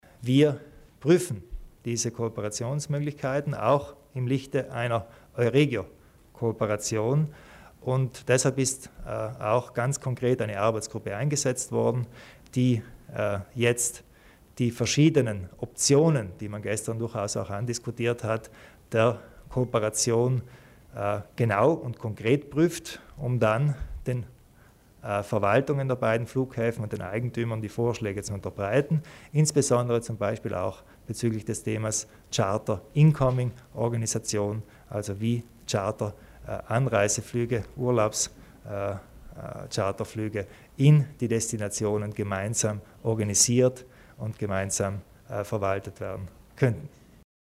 Landeshauptmann Kompatscher über den Flughafen Bozen